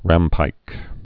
(rămpīk)